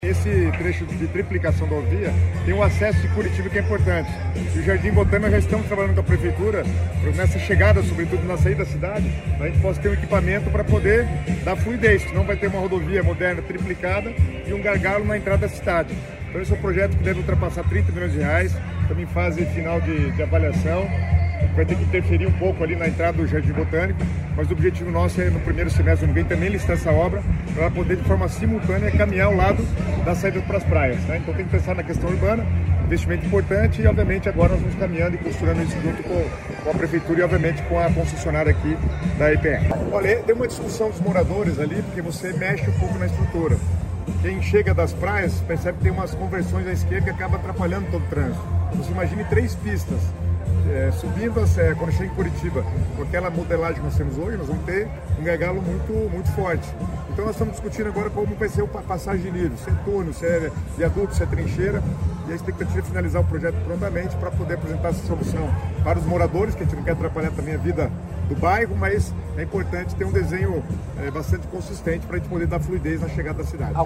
Sonora do secretário Estadual das Cidades, Guto Silva, sobre as obras de novas faixas na BR-277, entre Curitiba e São José dos Pinhais